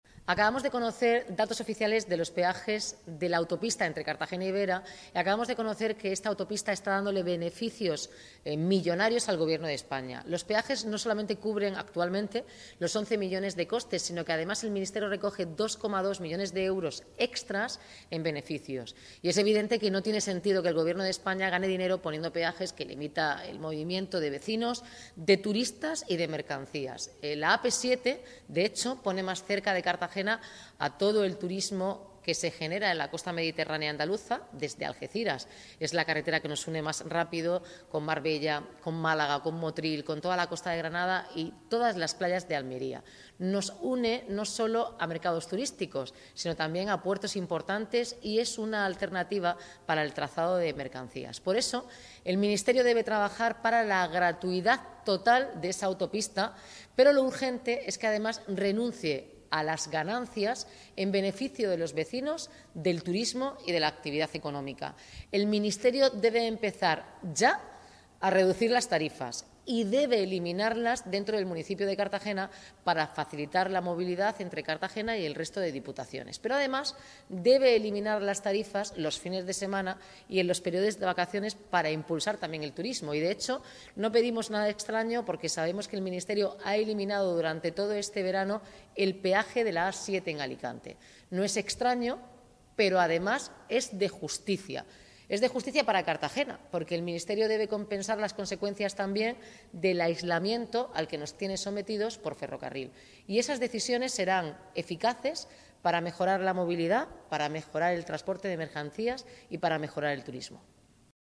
Enlace a Declaraciones de la alcaldesa pidiendo al Ministerio gratuidad en la AP7 para vecinos y turismo tras conocerse que da beneficios